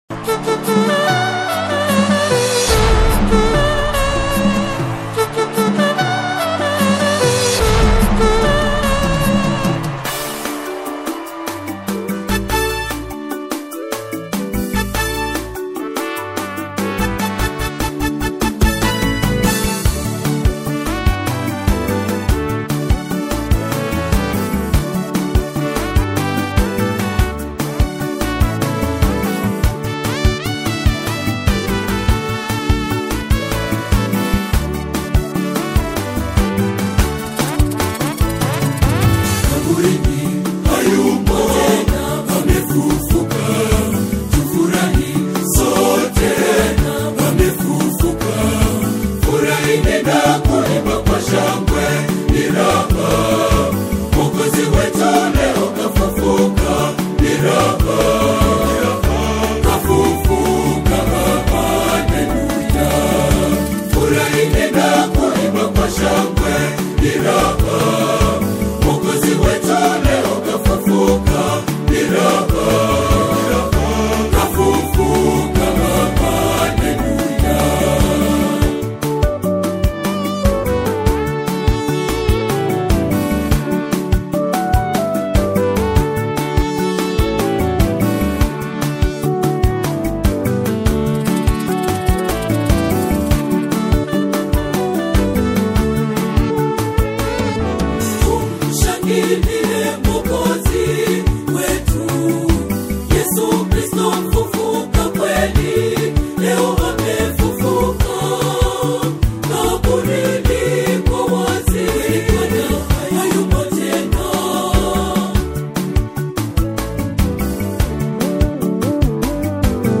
a powerful and spirit-filled anthem